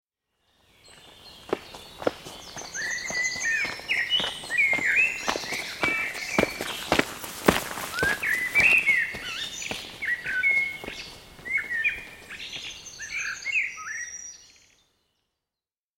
دانلود آهنگ پرنده 38 از افکت صوتی انسان و موجودات زنده
دانلود صدای پرنده 38 از ساعد نیوز با لینک مستقیم و کیفیت بالا
جلوه های صوتی